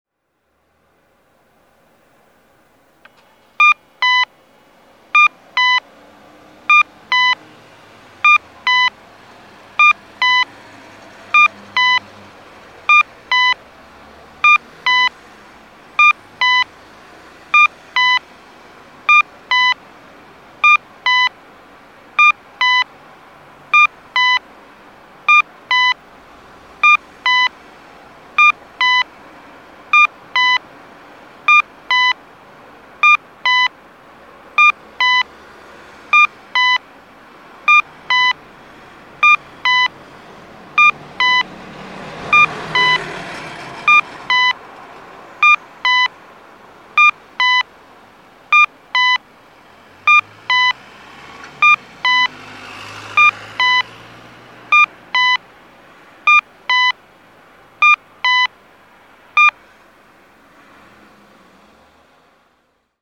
交通信号オンライン｜音響信号を録る旅｜三重県の音響信号｜[松阪:017]大黒田町交差点
大黒田町交差点(三重県松阪市)の音響信号を紹介しています。